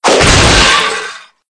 ENC_cogfall_apart_6.ogg